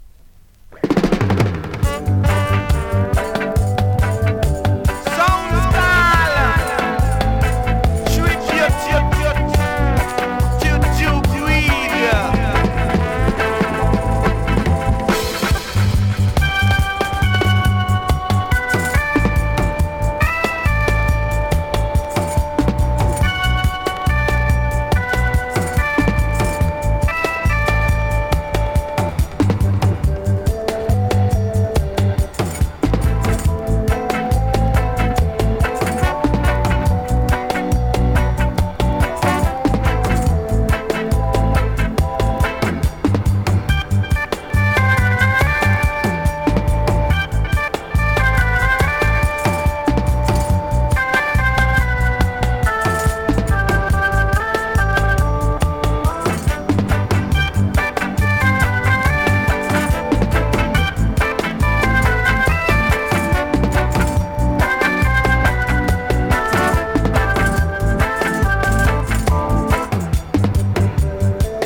ホーム > 2019 NEW IN!!SKA〜REGGAE!!
スリキズ、ノイズかなり少なめの